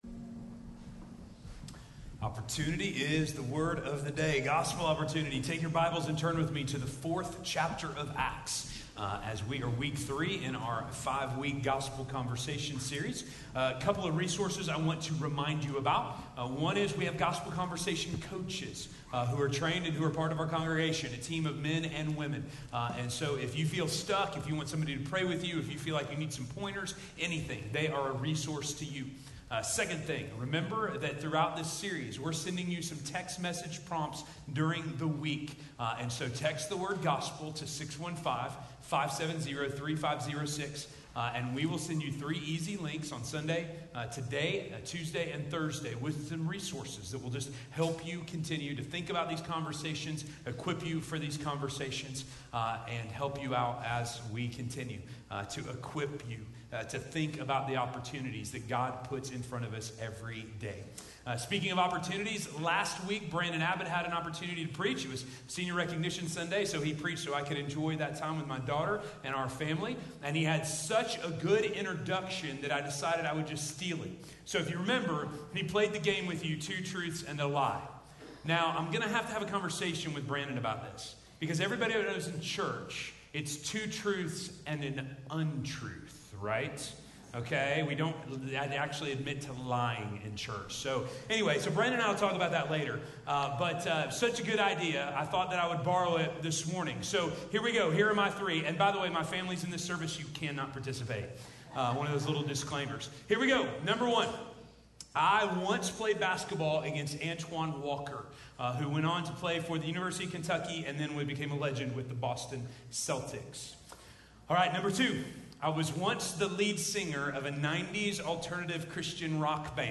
With Jesus - Sermon - Station Hill